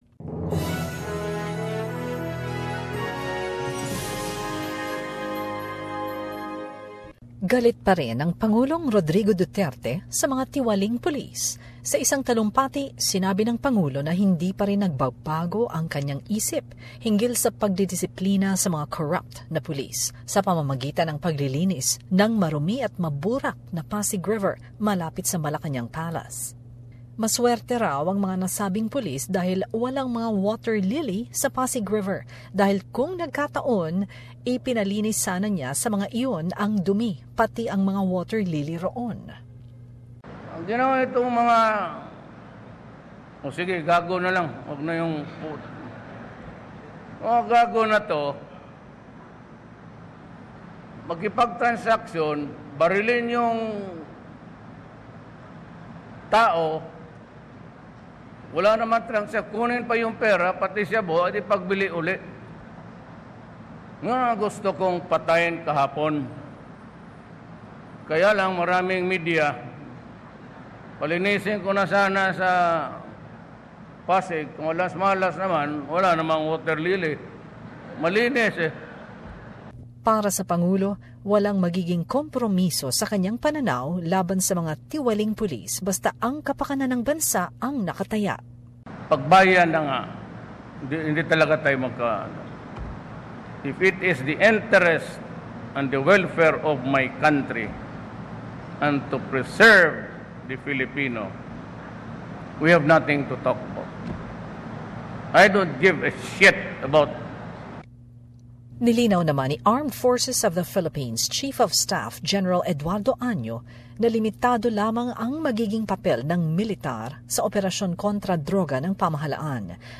Summary of relevant news in Manila